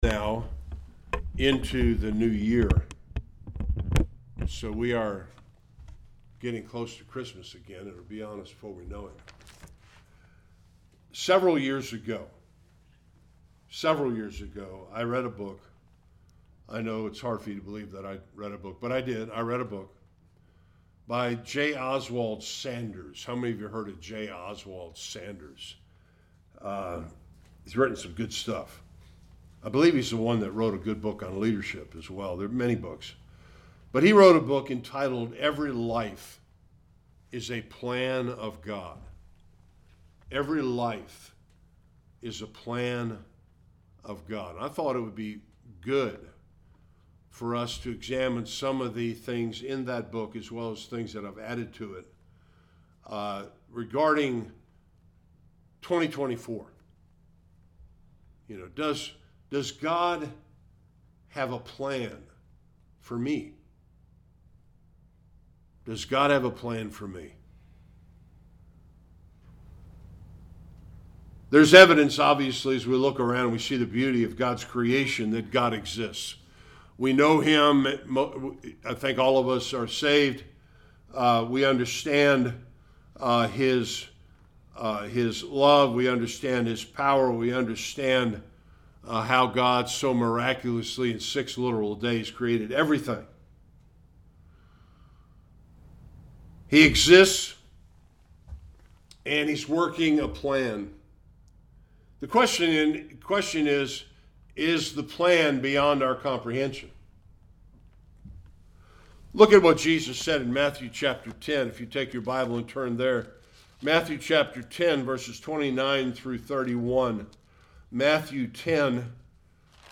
Various Passages Service Type: Sunday Worship Does God really have a plan for each believer’s life?